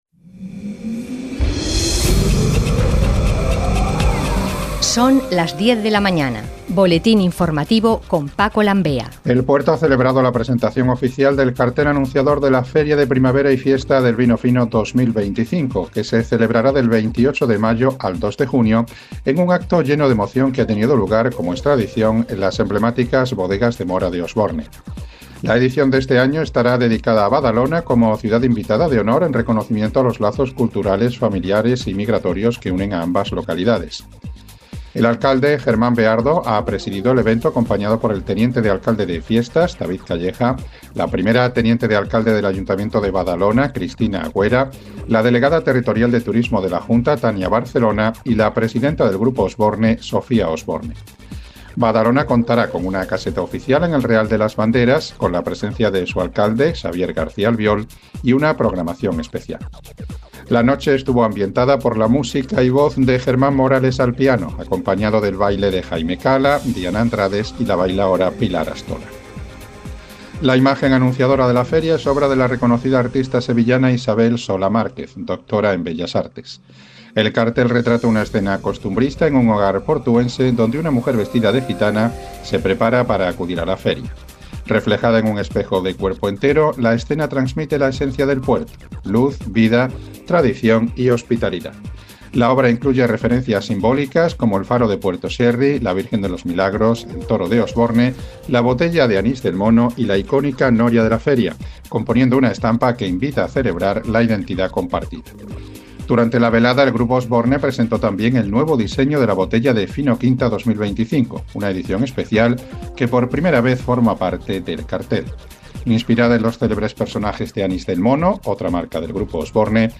Boletines Informativos
boletin_10_de_la_manana.mp3